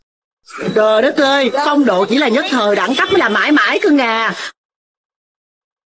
Thể loại: Câu nói Viral Việt Nam
sound effects, âm thanh hoạt hình, căng thẳng hồi hộp, nhạc chuông, tiếng ăn uống, âm thanh meme, nhạc chuông tin nhắn, tiếng Cười, meme sound effects, âm thanh đánh nhau, tiếng Súng, bất ngờ ngạc nhiên, tiếng trong nhà bếp, âm thanh kinh dị, câu nói Viral, âm thanh vui nhộn hài hước